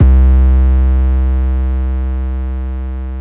TM88 EnemyDist808.wav